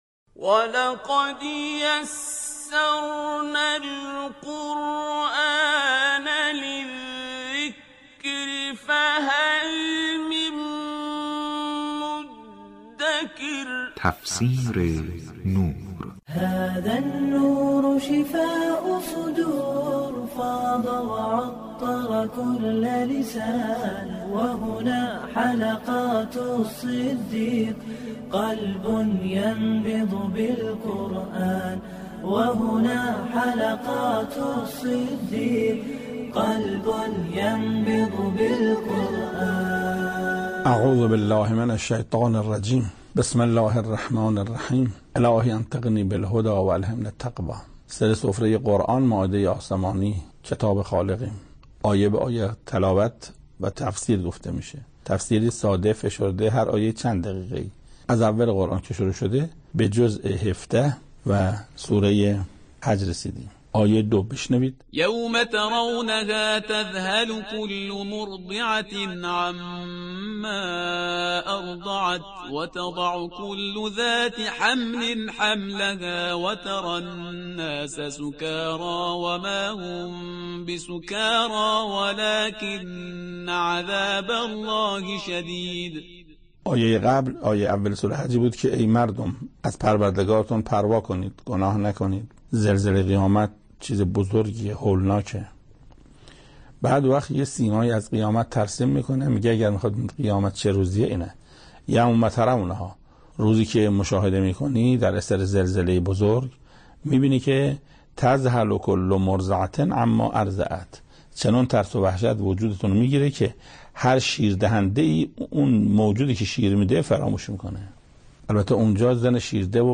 به گزارش خبرگزاری حوزه، استاد قرائتی در برنامه تفسیر نور به تفسیر آیاتی از سوره حج با موضوع «روز قیامت؛ روز محو شدن عقل و عاطفه» پرداخته است که تقدیم شما فرهیختگان می شود.